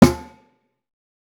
TC SNARE 04.wav